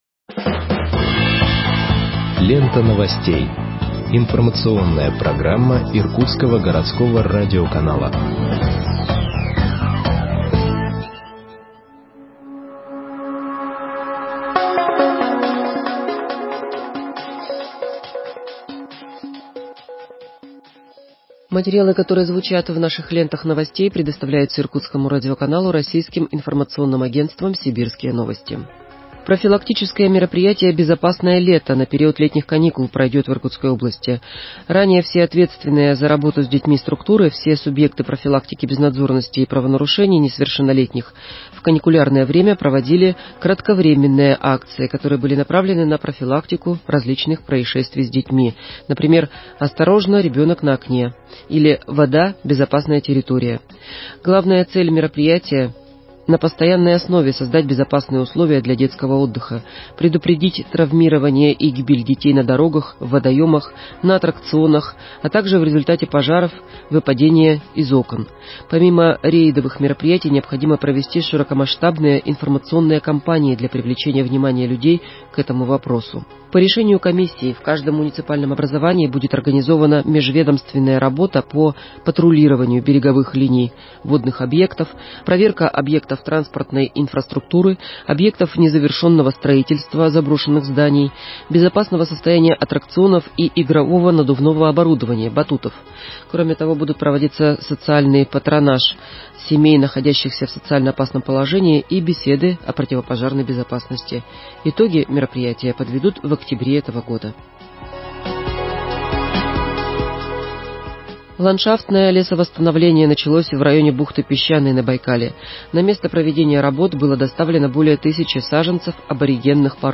Выпуск новостей в подкастах газеты Иркутск от 03.06.2021 № 1